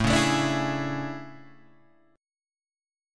A Major 11th
AM11 chord {5 4 7 7 5 4} chord